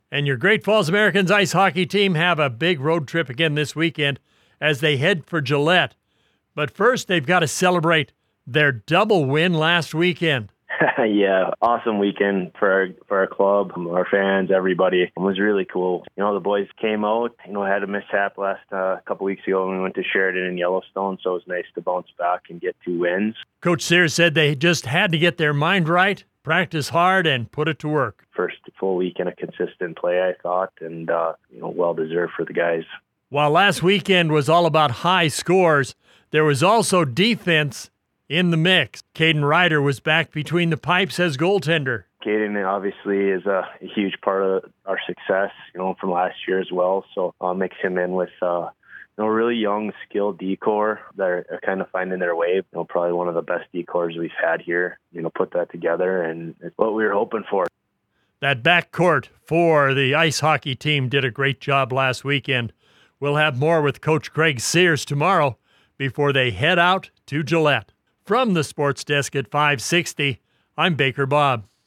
The Weekly Radio Interview on 560AM KMON in Great Falls can be heard every Wednesday, Thursday and Friday mornings during the Hourly Sports Report (6:15AM (MST), 7:15AM (MST), and 8:15AM (MST) live.